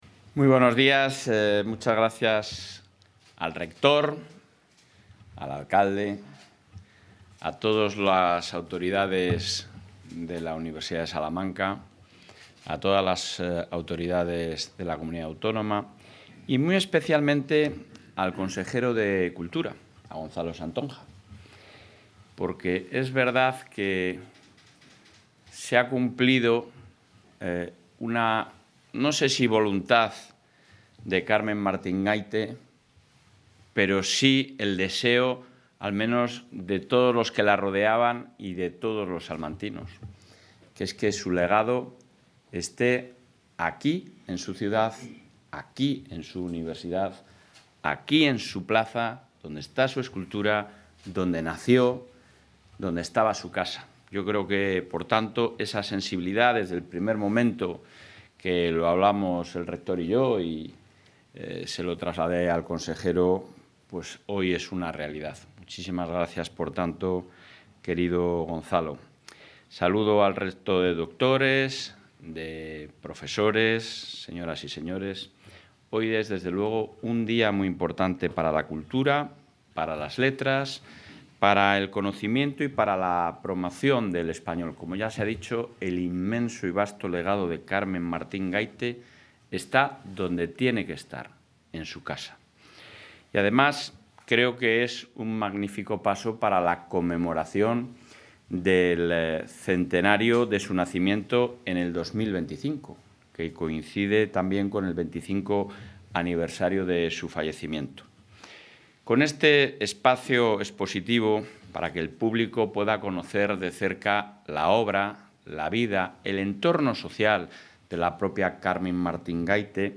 Intervención del presidente de la Junta.
El presidente de la Junta de Castilla y León, Alfonso Fernández Mañueco, ha participado hoy en Salamanca en el acto de recepción del legado de la escritora Carmen Martín Gaite al Centro Internacional del Español.